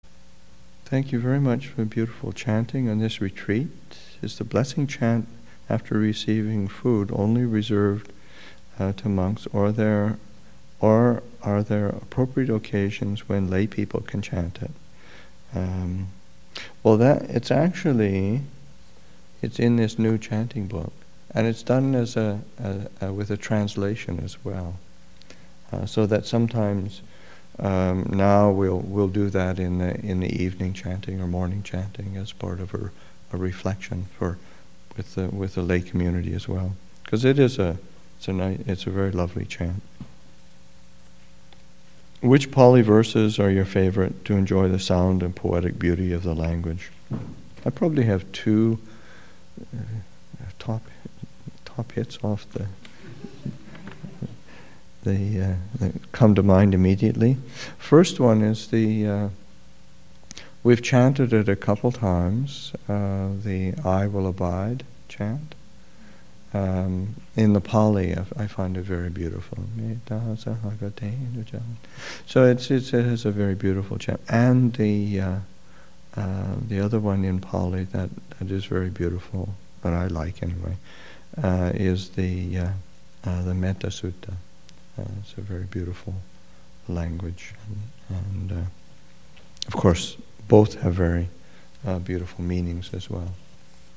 Thanksgiving Retreat 2016, Session 8 – Nov. 26, 2016